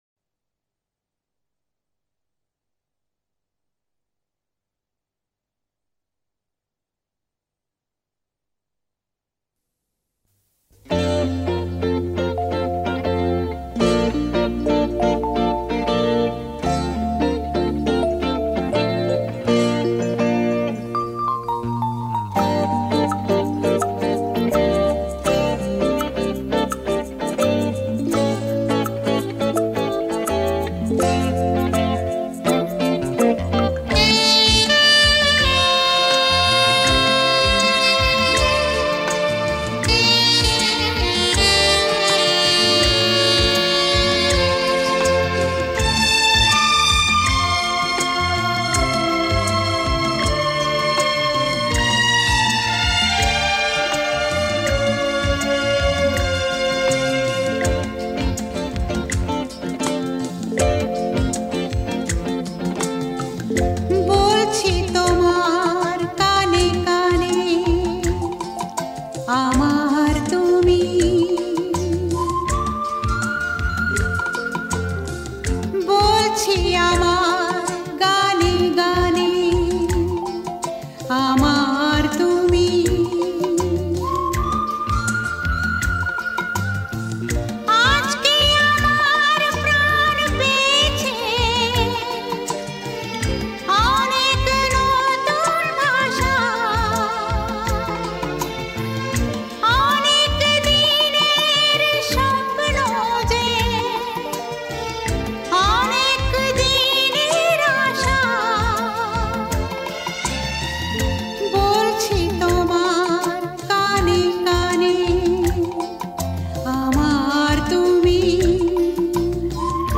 Songs Mp3 Bengali